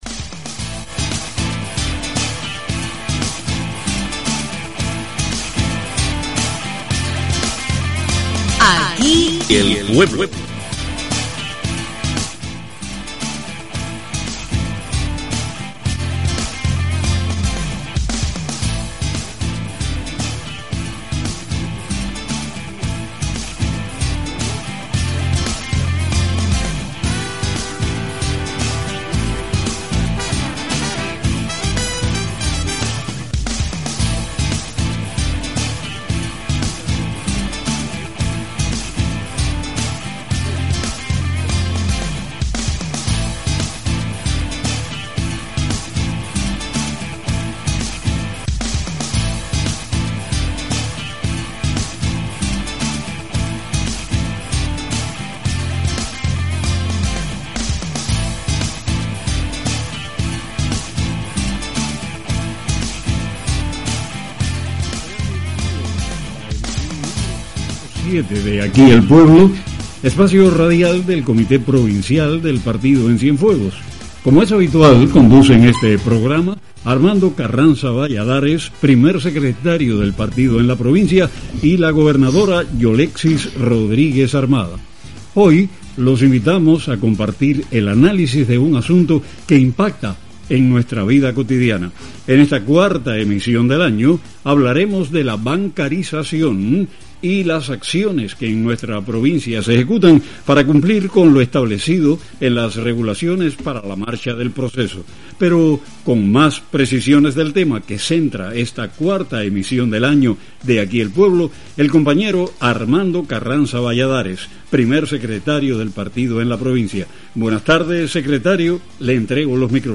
Conducido por las máximas autoridades del Partido Comunista de Cuba (PCC) y el Gobierno, el espacio abordó los problemas que impiden el avance de este proceso y las acciones que corresponden a los organismos e instituciones implicadas con el propósito de revertir las fallas en este escenario importante para el desarrollo de la economía.
Mediante llamadas telefónicas, los oyentes compartieron sus vivencias cotidianas: desde la negativa de algunos actores económicos (privados y estatales) a recibir pagos con tarjeta o mediante código QR, hasta la imposibilidad de extraer dinero en los bancos.